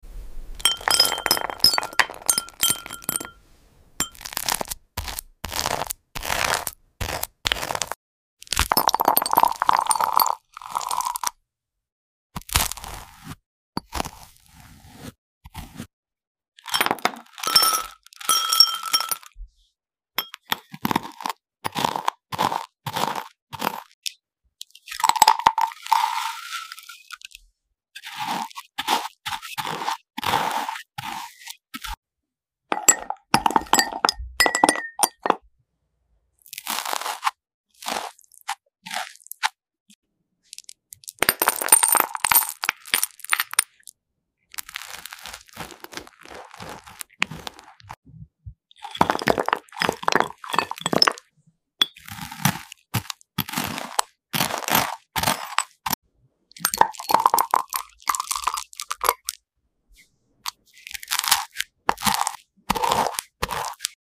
Which strawberry ASMR spread is